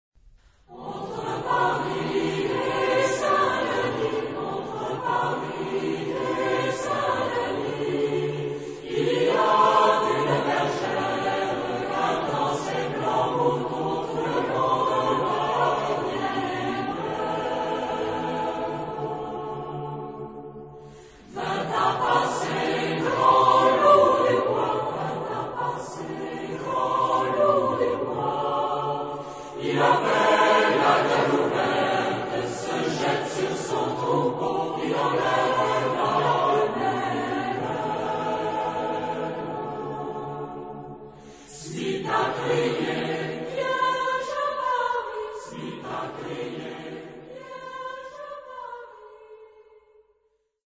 Genre-Style-Form: Popular ; Secular
Mood of the piece: moderate
Type of Choir: SATB  (4 mixed voices )
Tonality: A dorian